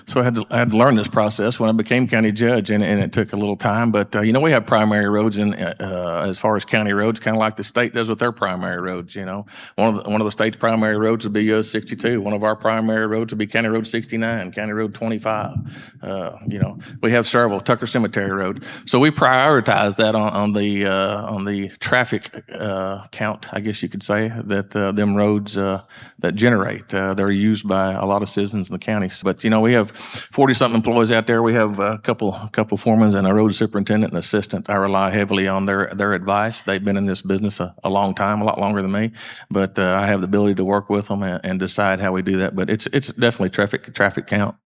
The public forum held at the Food Bank of North Central Arkansas saw County Judge Kevin Litty face challenger Eric Payne who currently serves as Justice of the Peace for District 11.
With more than 700 miles of roads in Baxter County the candidates were asked how they would prioritize which roads are maintained and improved across the county. Judge Litty was up first to answer.